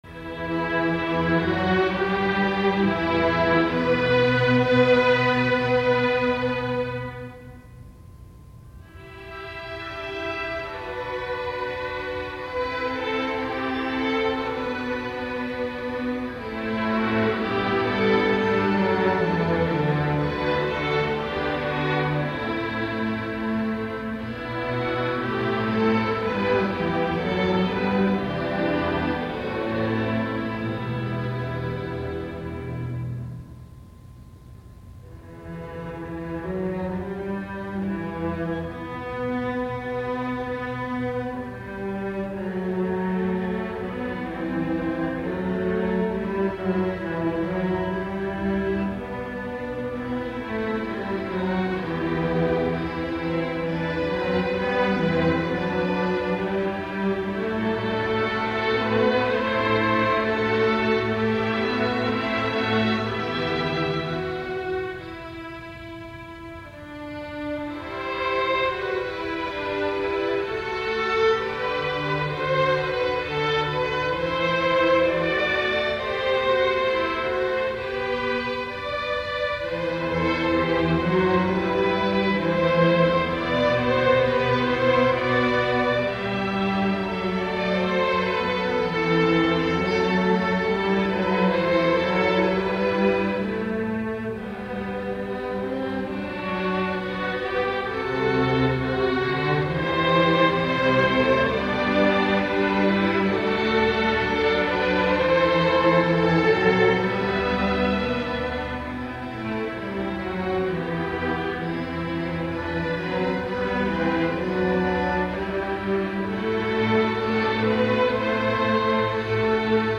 Choral et fugue sur une mélodie de Noël,
cette pièce est d'un caractère calme et méditatif.